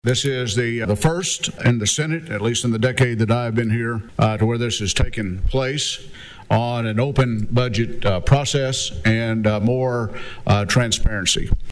CLICK HERE to listen to commentary from Committee Chairman Roger Thompson.